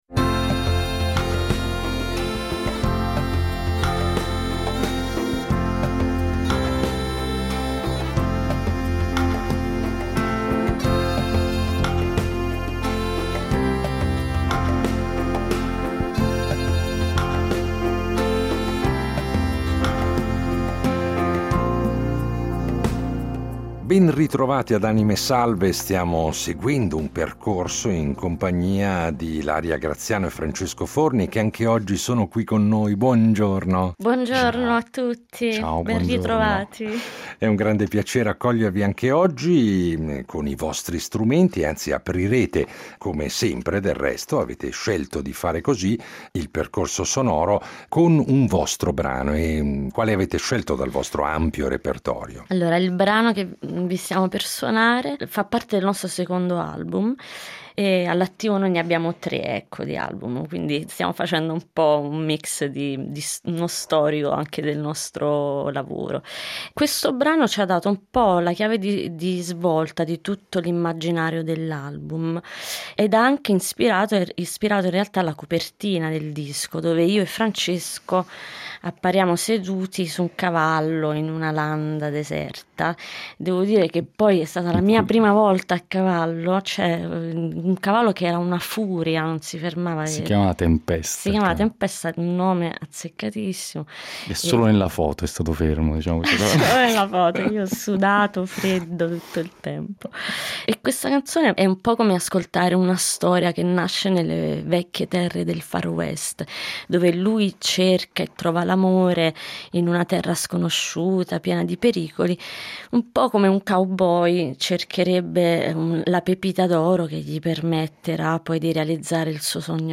due voci, una chitarra, un ukulele e tante idee
Per nostra fortuna hanno portato i loro strumenti, quindi ce ne daranno un saggio esclusivo.